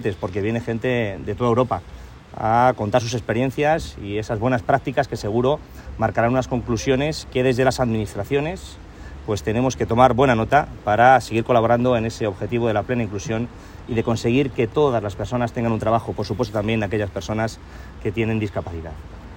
Cortes de voz
corte-de-voz-velazquez-participa-en-la-jornada-organizada-por-cocemfe-3.m4a